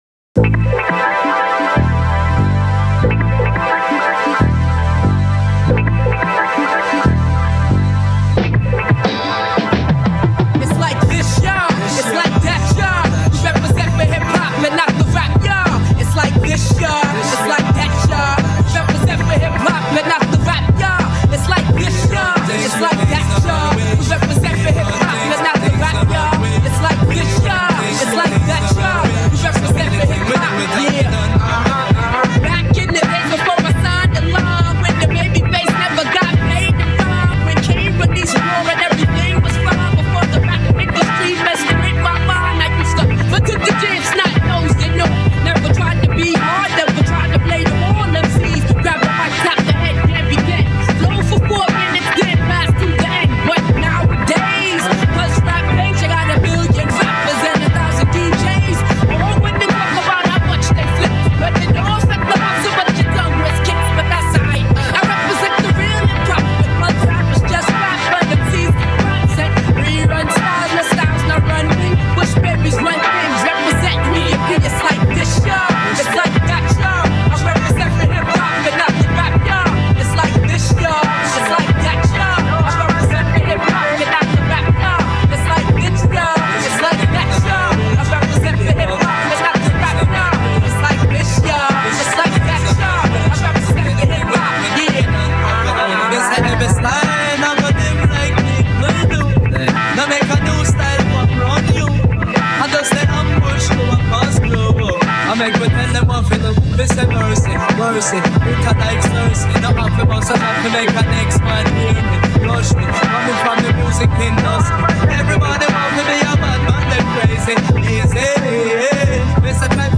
Your tracks are heavy.
Yes everything is from the track.